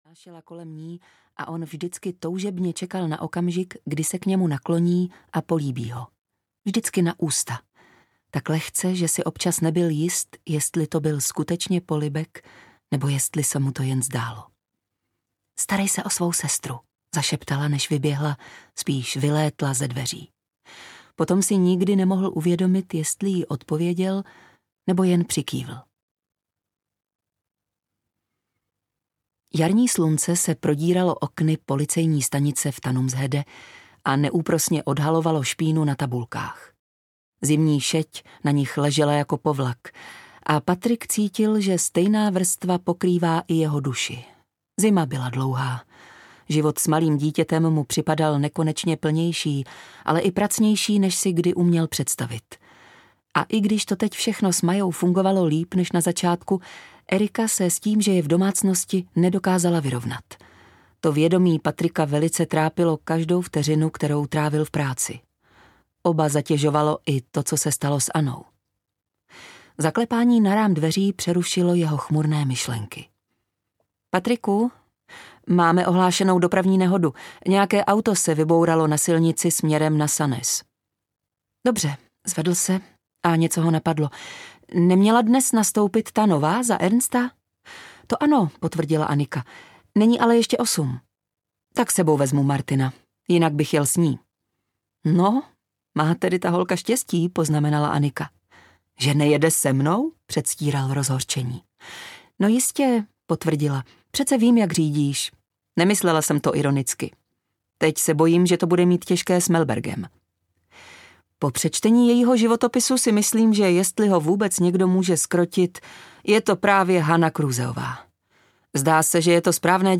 Vlastní spravedlnost audiokniha
Ukázka z knihy